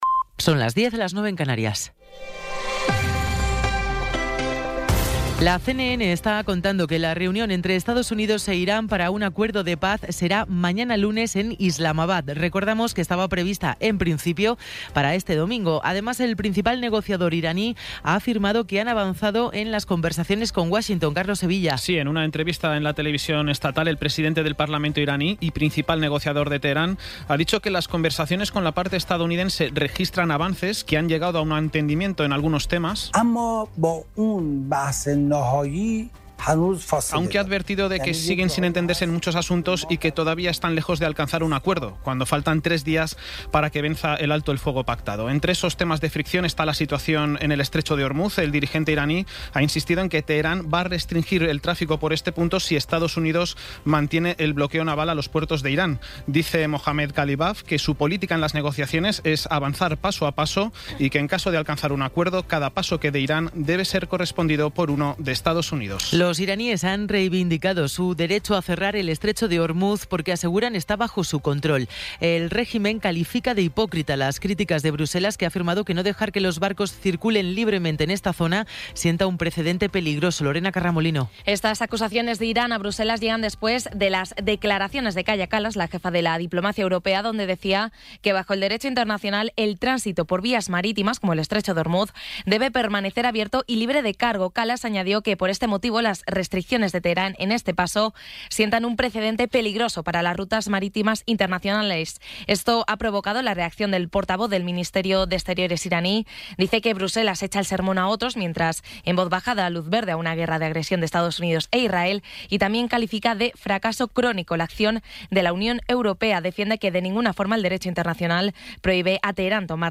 Resumen informativo con las noticias más destacadas del 19 de abril de 2026 a las diez de la mañana.